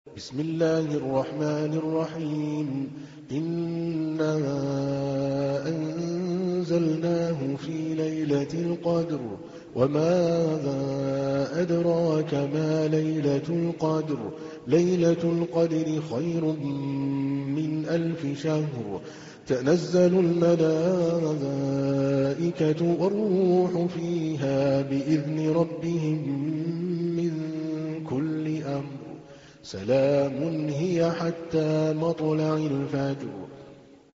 تحميل : 97. سورة القدر / القارئ عادل الكلباني / القرآن الكريم / موقع يا حسين